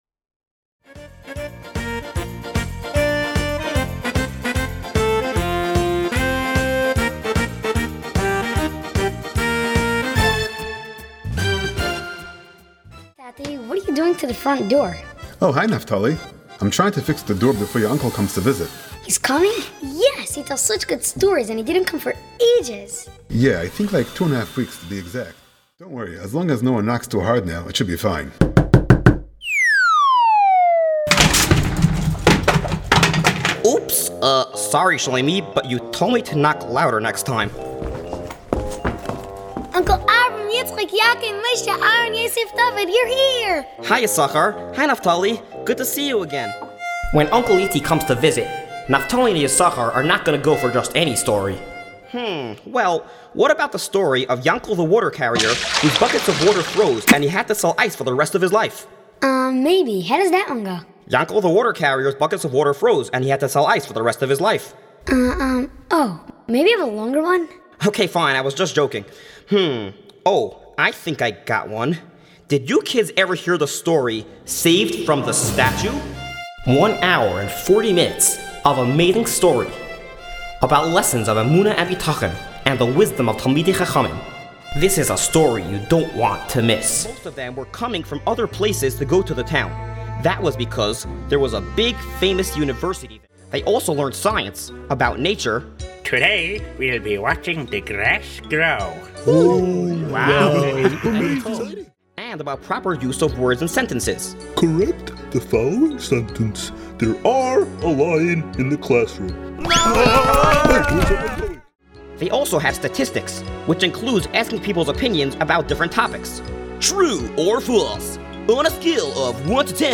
Follow a story said to have happened with the Ibn Ezra, how the wisdom of talmidei chachamim saved the entire town from the impassable and seemingly unbeatable statue. With over an hour and ten minutes of story line, and 4 original hilarious and inspirational songs, this is one story that even adults can shamelessly enjoy!
Very clever rhymes. catchy tunes. even adults can appreciate while the children play over and over again. very well done!